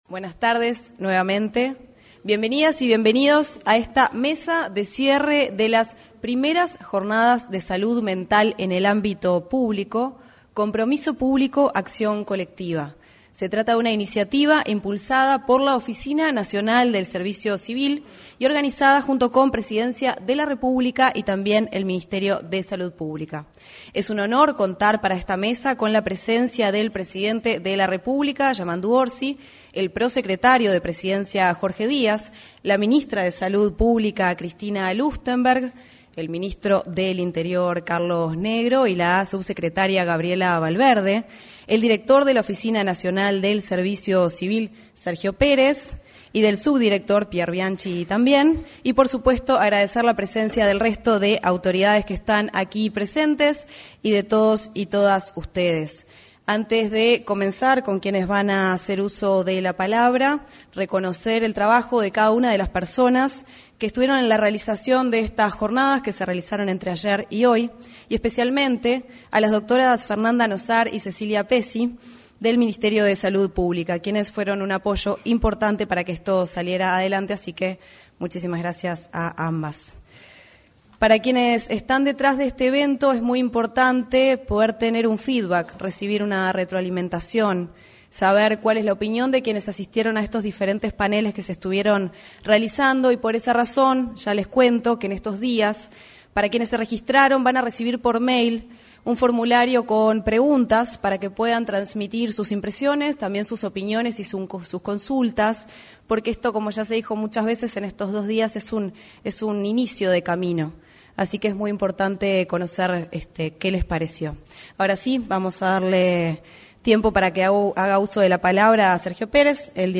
Primeras Jornadas de Salud Mental en el Ámbito Público 28/10/2025 Compartir Facebook X Copiar enlace WhatsApp LinkedIn Se realizó, con la presencia del presidente de la República, Yamandú Orsi, el cierre de las Primeras Jornadas de Salud Mental en el Ámbito Público. En la oportunidad, se expresaron el director de la Oficina Nacional del Servicio Civil, Sergio Pérez, y la ministra de Salud Pública, Cristina Lustemberg.